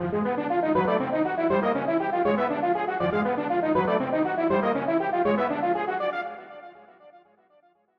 フリー効果音｜ジャンル：システム、ゲーム系効果音、第46弾！
何かが現れて近づいてくる時に流れてそうなゴーストっぽい効果音、SEです！